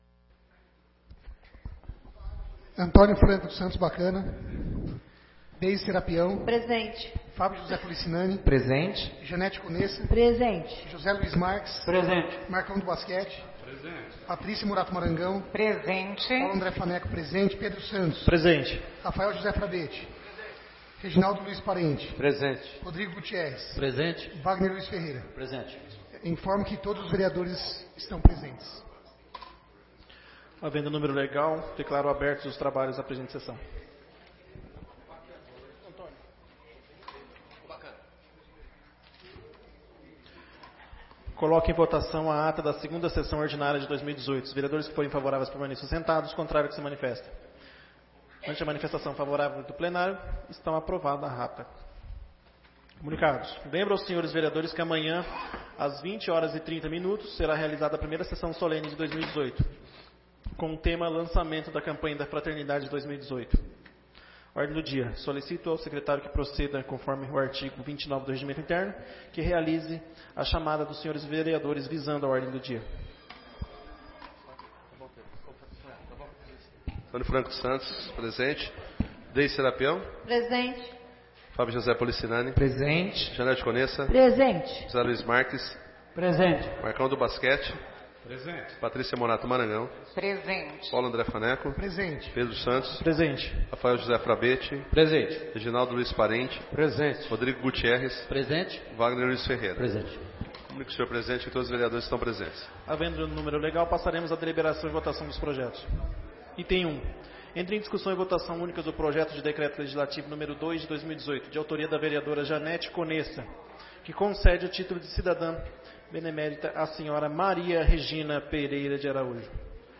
3ª Sessão Ordinária de 2018